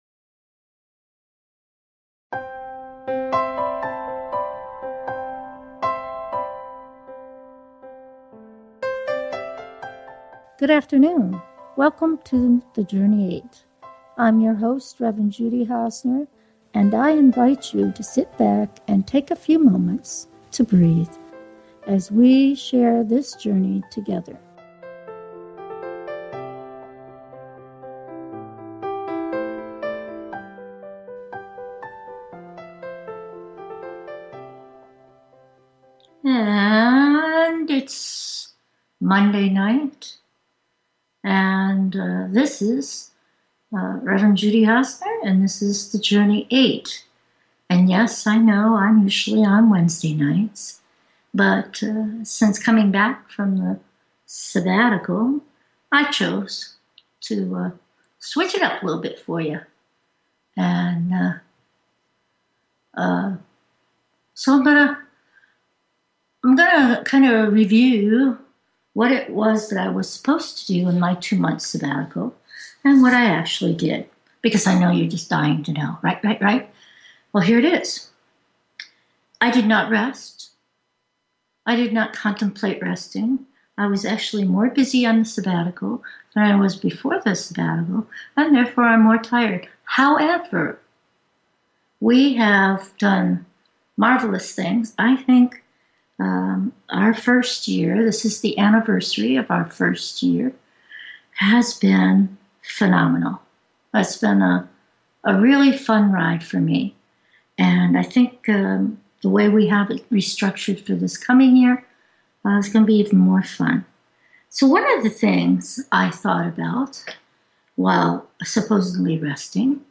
The Journey 8 is a Christian based talk show that crosses the barriers of fear and hatred to find understanding and tolerance for all of God’s children.